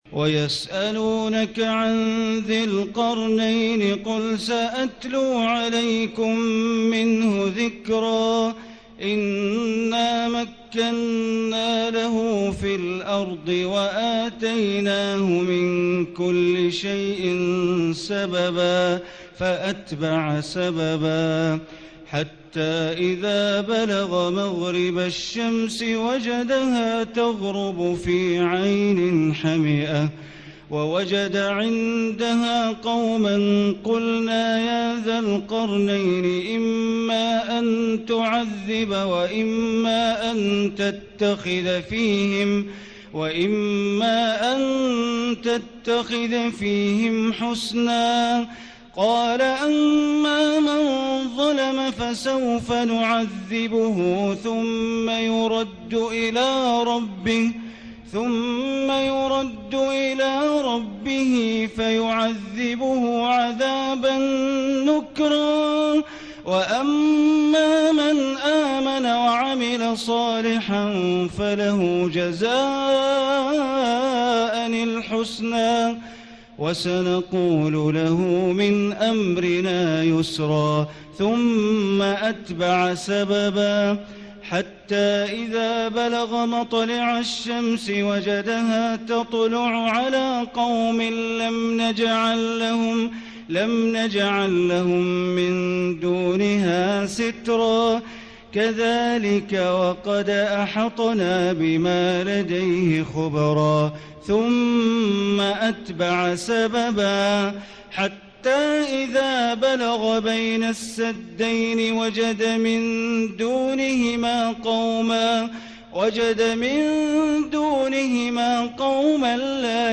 ليلة 15 من رمضان عام 1436 من سورة الكهف آية 83 إلى سورة مريم كاملة > تراويح ١٤٣٦ هـ > التراويح - تلاوات بندر بليلة